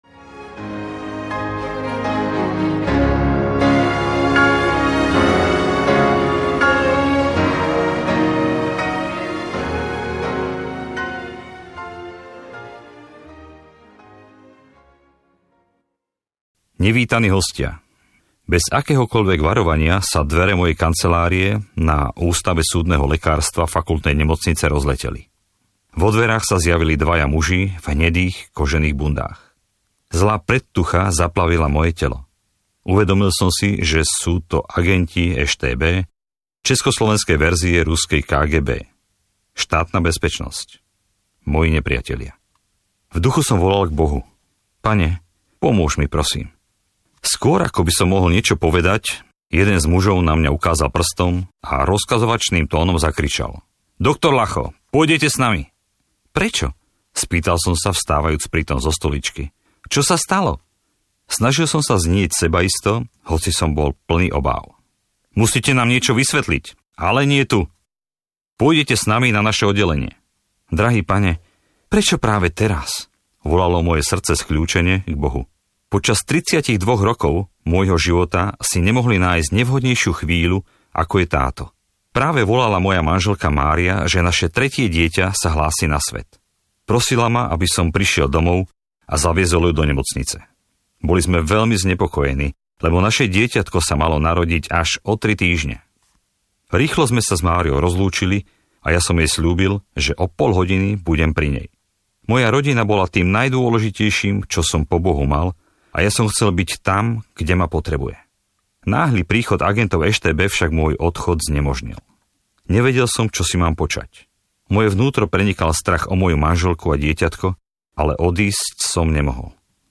Kroky viery audiokniha
Ukázka z knihy